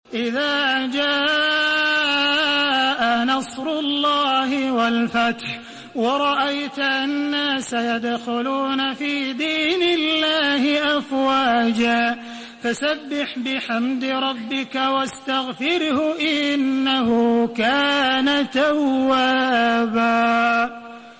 Surah আন-নাসর MP3 in the Voice of Makkah Taraweeh 1432 in Hafs Narration
Murattal Hafs An Asim